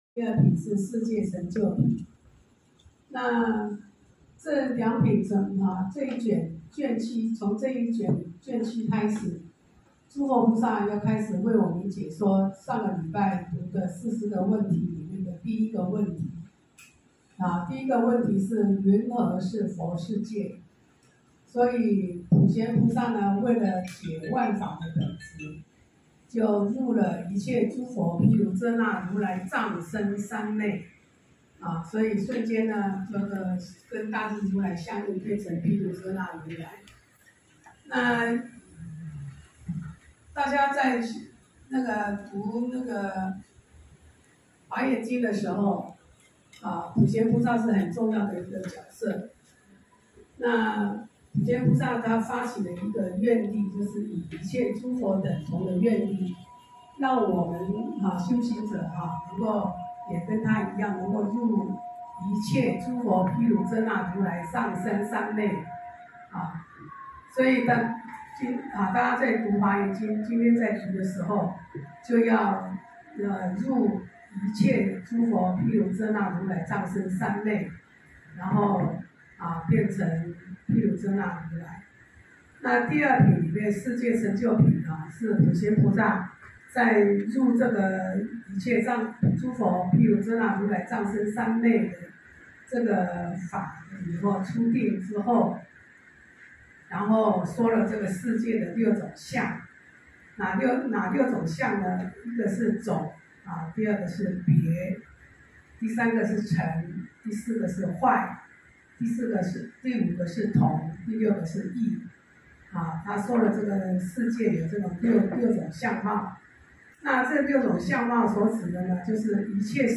週六共修---讀誦華嚴經第7卷